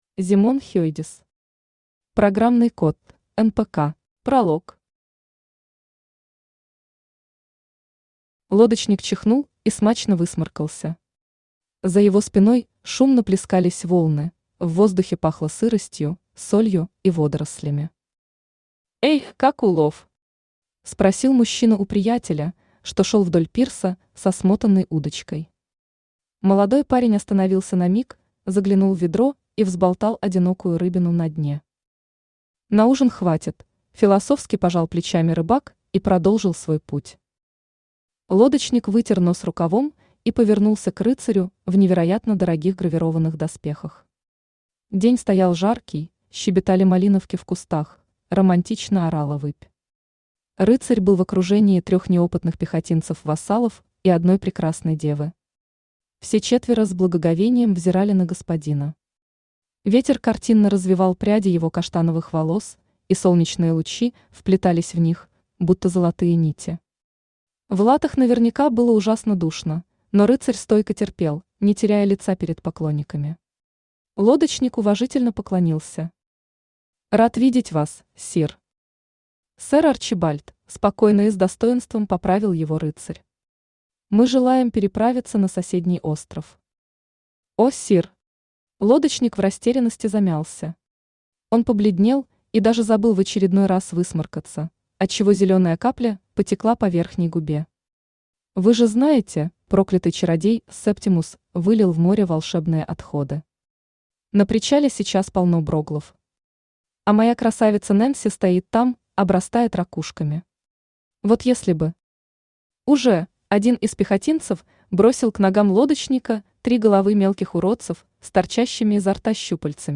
Aудиокнига Программный код: NPC Автор Зимон Хейдес Читает аудиокнигу Авточтец ЛитРес.